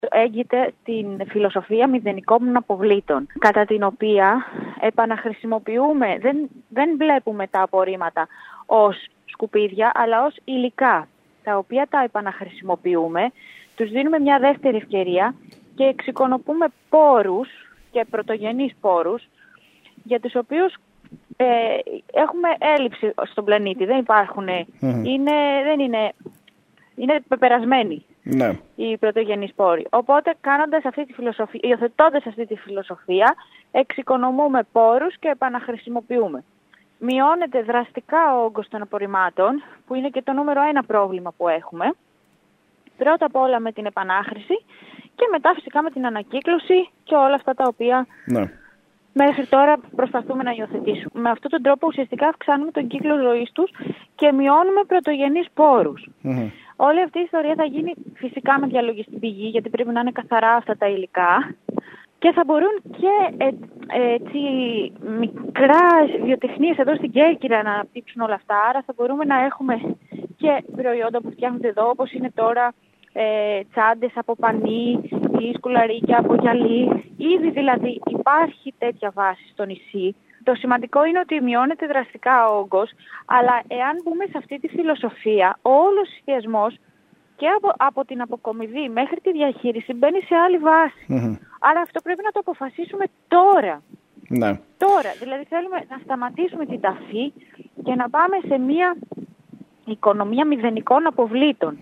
Η επικεφαλής της παράταξης Κέρκυρα 180 Μαρία Δρυ, μιλώντας στην ΕΡΤ, αναφέρθηκε στα πλεονεκτήματα της μεθόδου η οποία οδηγεί στην επανάχρηση όλων των ανακυκλώσιμων υλικών αλλά απαιτεί συνολική αλλαγή του τρόπου διαχείρισης της καθαριότητας.